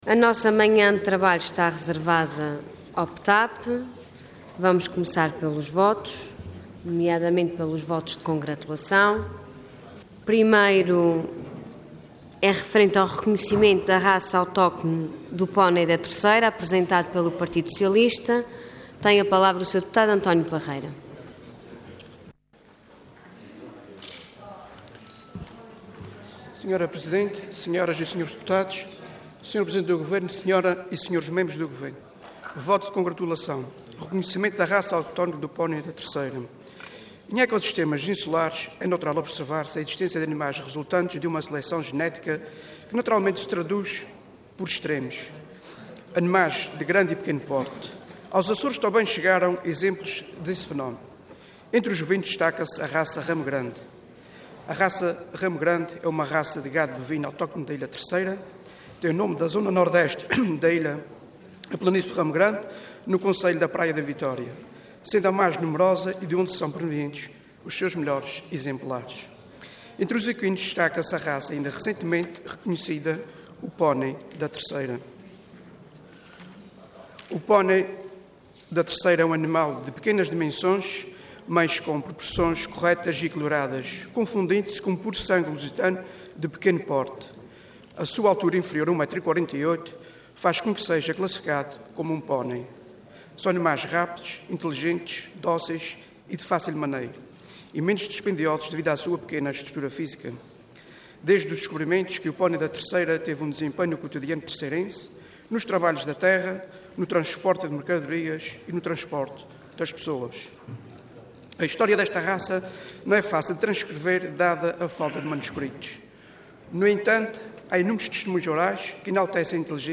Detalhe de vídeo 13 de fevereiro de 2014 Download áudio Download vídeo Processo X Legislatura Reconhecimento da Raça Autóctone do Pónei da Terceira Intervenção Voto de Congratulação Orador António Parreira Cargo Deputado Entidade PS